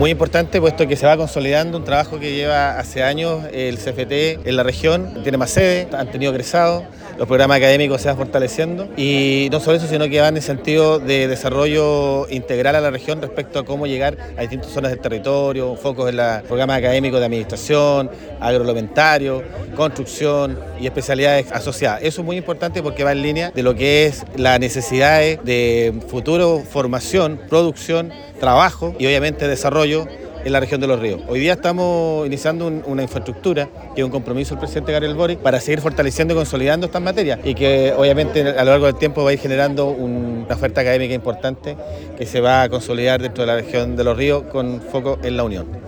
Delegado-Presidencial-Jorge-Alvial.mp3